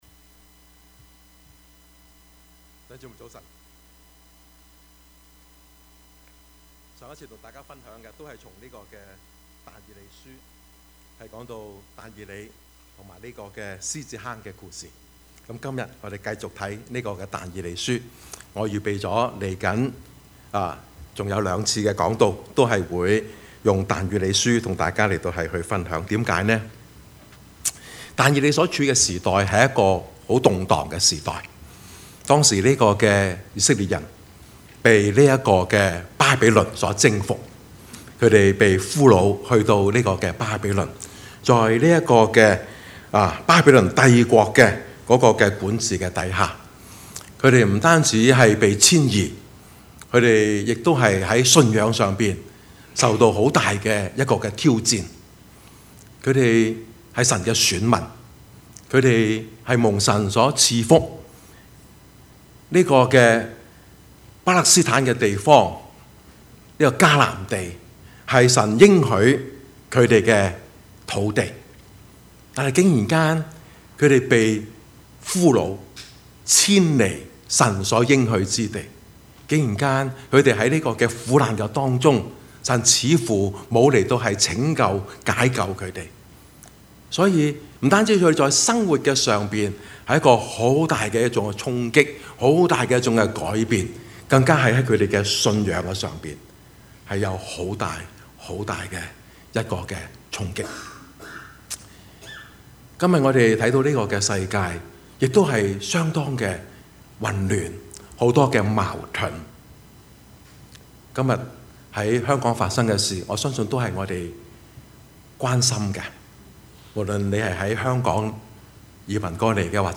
Service Type: 主日崇拜
Topics: 主日證道 « 持守信仰的決心和力量 務要傳道 »